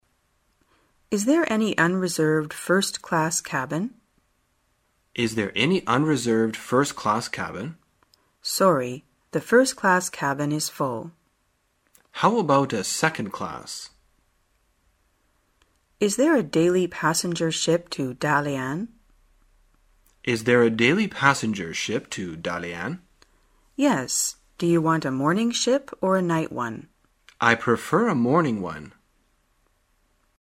在线英语听力室生活口语天天说 第129期:怎样购买船票的听力文件下载,《生活口语天天说》栏目将日常生活中最常用到的口语句型进行收集和重点讲解。真人发音配字幕帮助英语爱好者们练习听力并进行口语跟读。